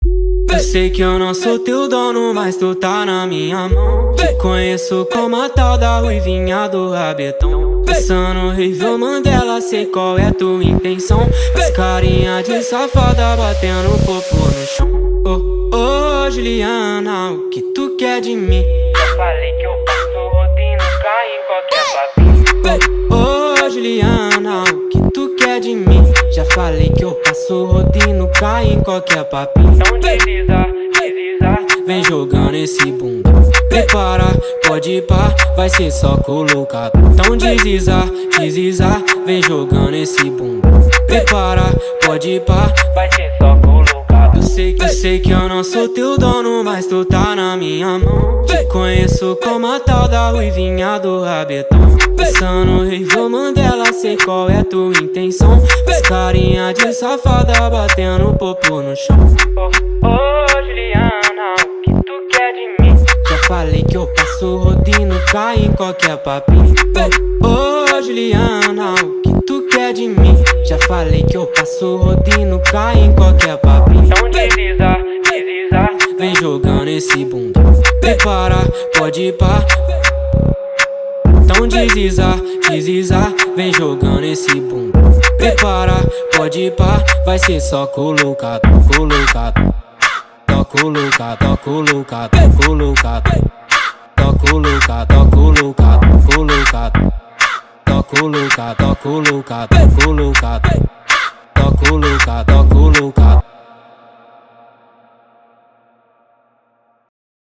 2024-04-09 20:12:37 Gênero: Phonk Views